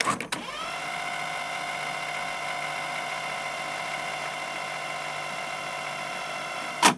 Casete retrocediendo rápido
cinta magnética casete rebobinar retroceder